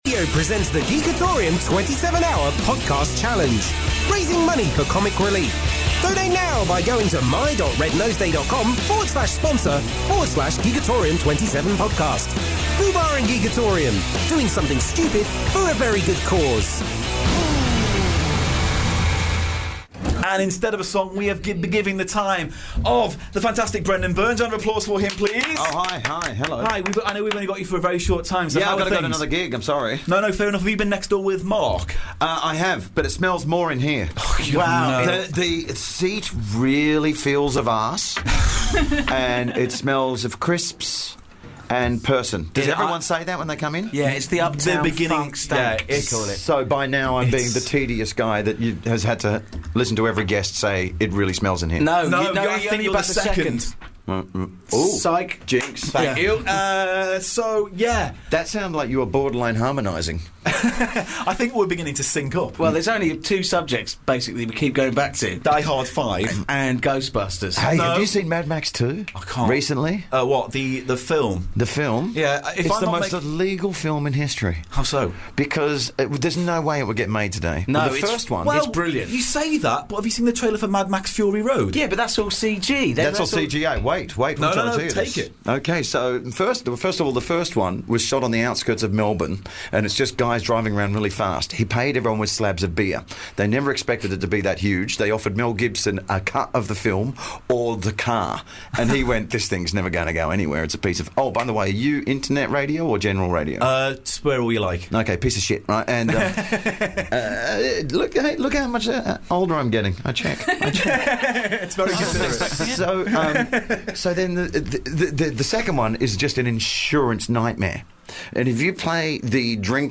A 27 hour comedy marathon broadcast in aid of Comic Relief! Packed with games, sketches, fund raising challenges and chat.